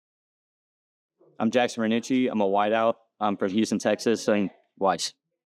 Pronunciation Guide